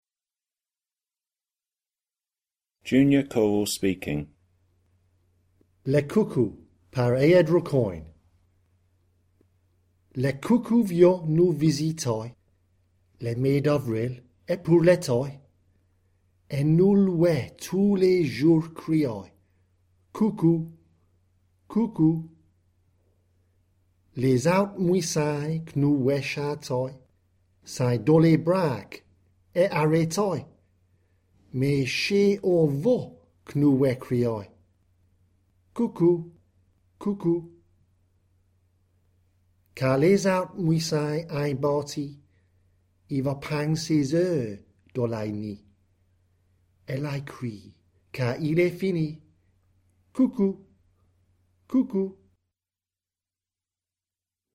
Guernsey French Eisteddfod Class 367 - Junior choral speaking